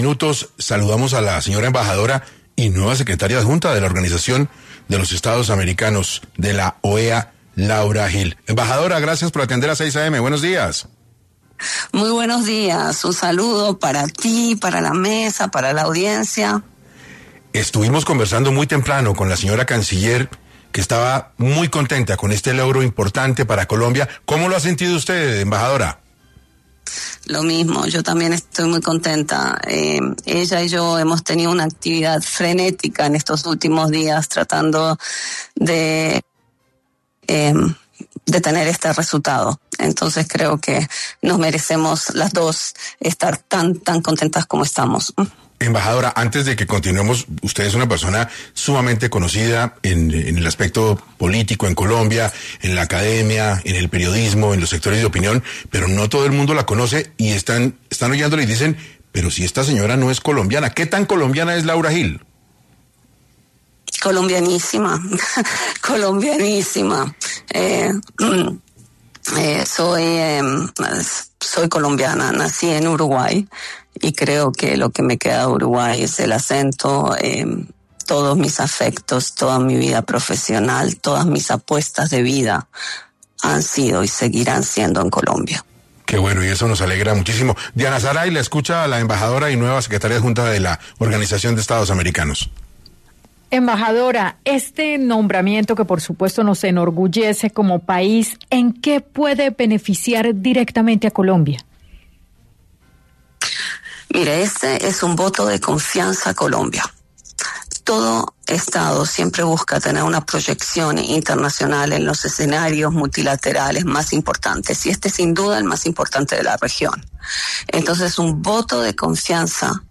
La Secretaria Adjunta de la OEA, Laura Gil, estuvo conversando con la mesa de trabajo a propósito de su elección en este organismo internacional.